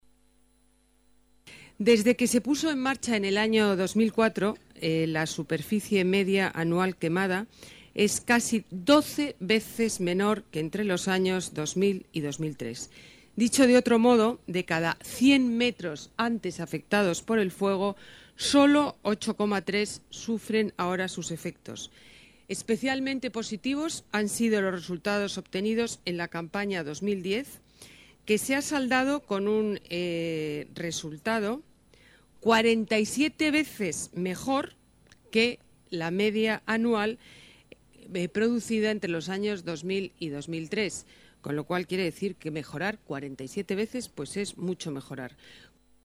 Nueva ventana:Ana Botella, delegada de Medio Ambiente, ha presentado el balance del operativo contra incendios en la Casa de Campo